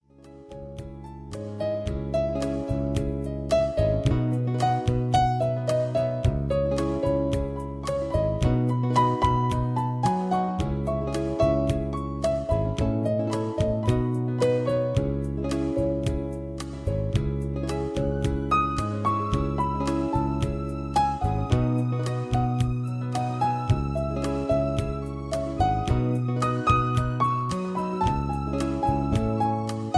Inspirational, Invigorating, reviving